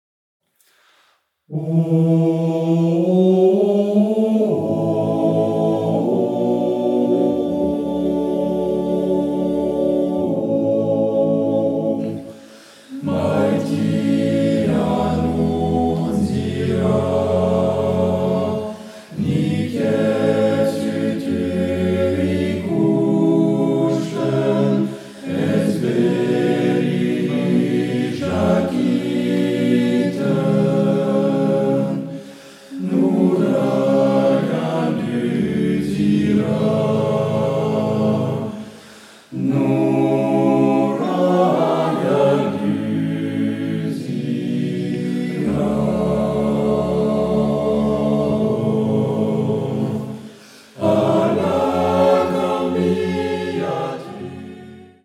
Octuor d’hommes